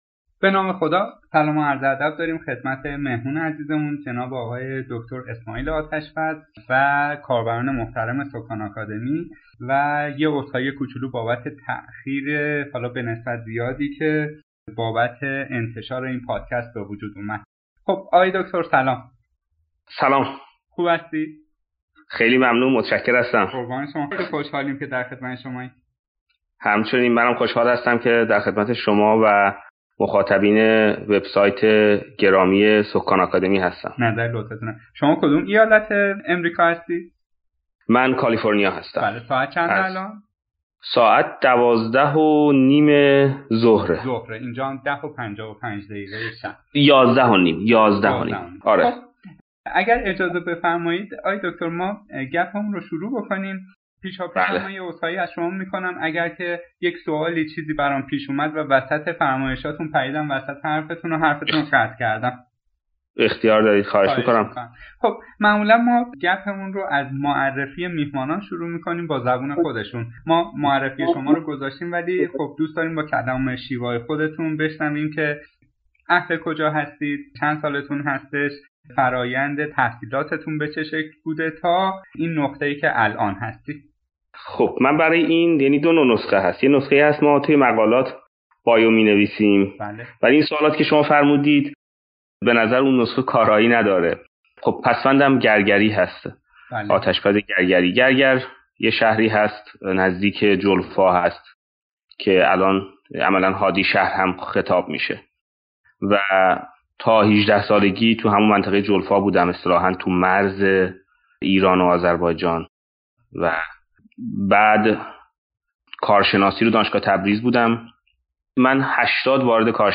به دلیل پرداخته شدن به بسیاری از سؤالات که دغدغه ذهنی بسیاری از دانشجویان و جوانان است، انتشار فایل صوتی این مصاحبه بر روی فرادرس را، مفید دانستیم.